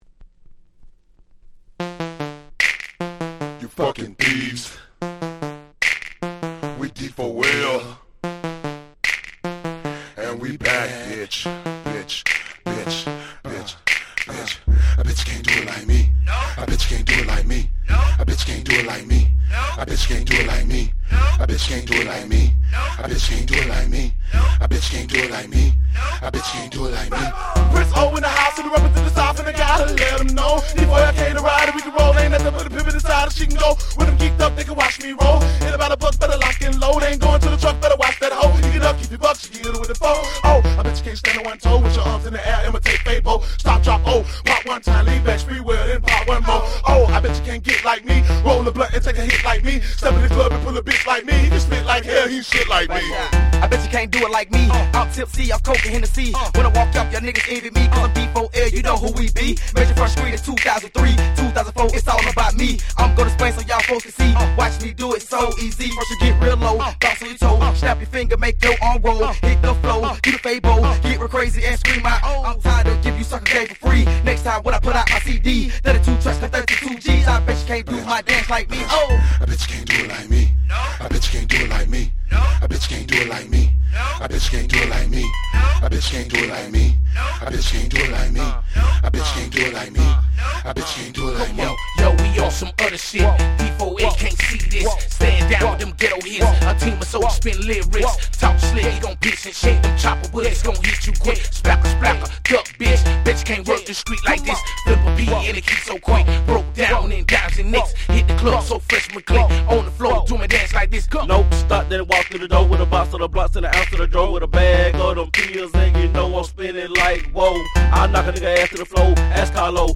05' Super Hit Souther Hip Hop !!
サウスクラシック！！
この辺の音スカスカなサウス、今のTrap物なんかともバッチリ通じますよね！